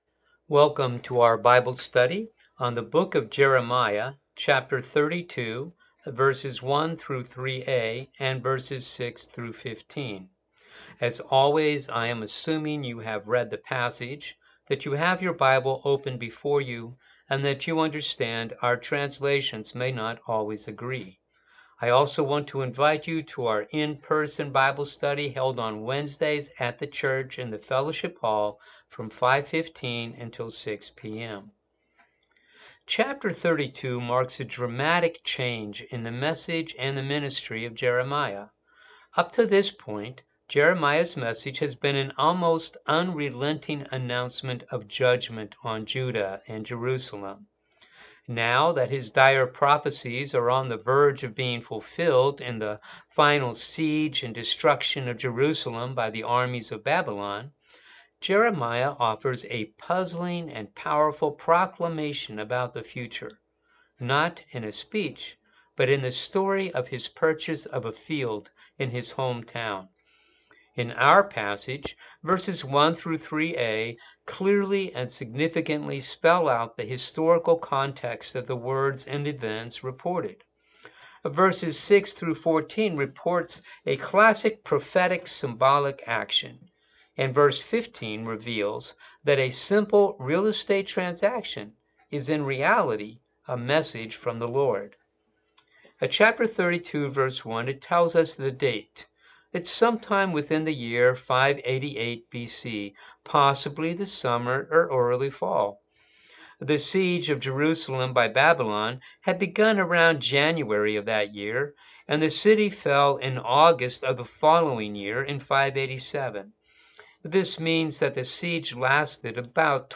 Bible Study for the Sept 25 Service